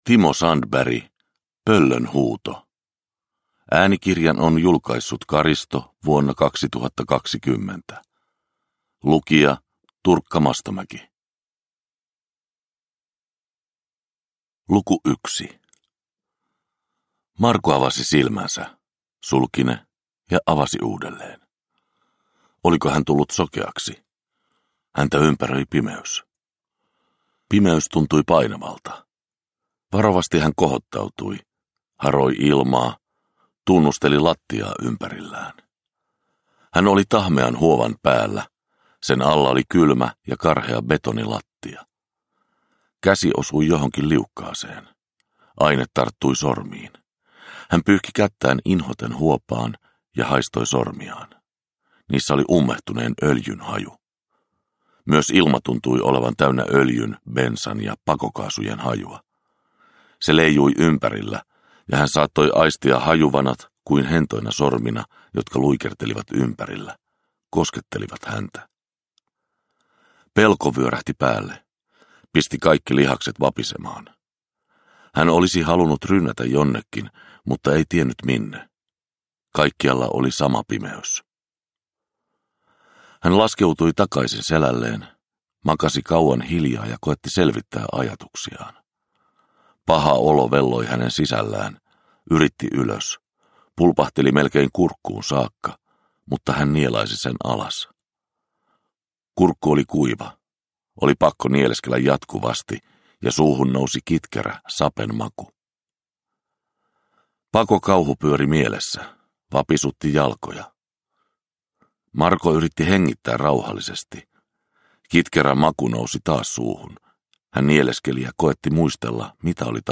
Pöllön huuto – Ljudbok – Laddas ner